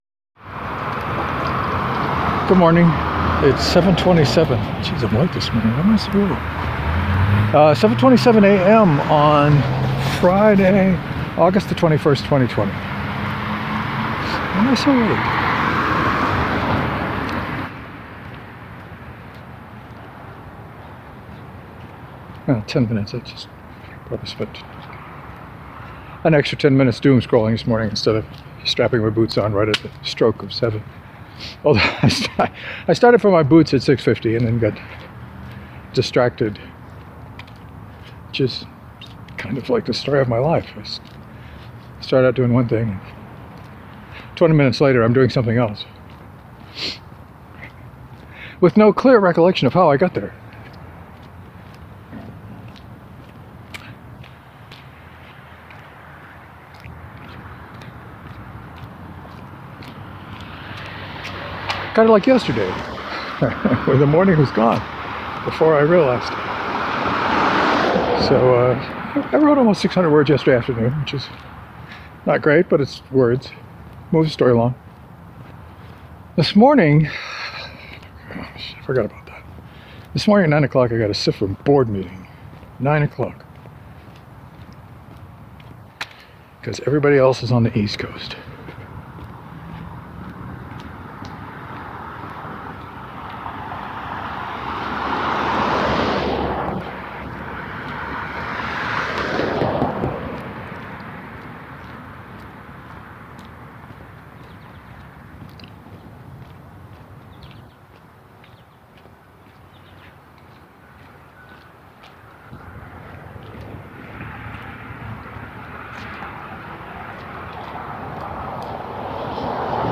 I talked about Kickstarter and Patreon a little today but mostly I just dodged cars and dog-walkers.